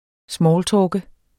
Udtale [ ˈsmɒːlˌtɒːgə ]